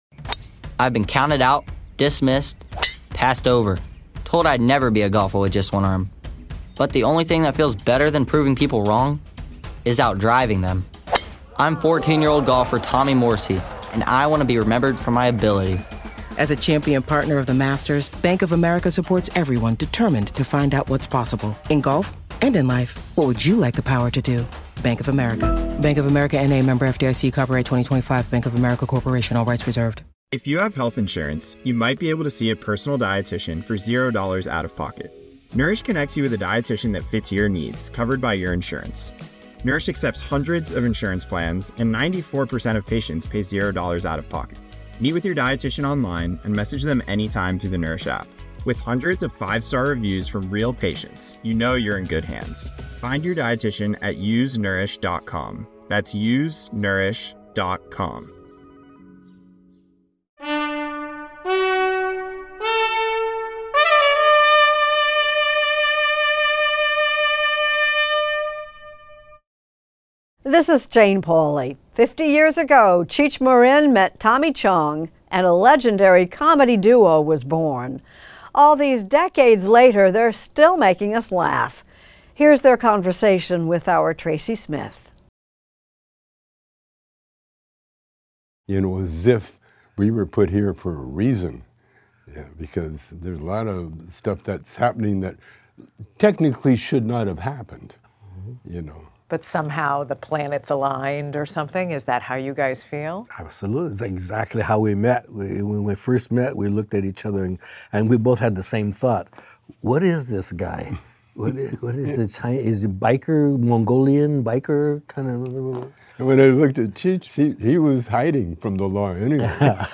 Extended Interview: Cheech and Chong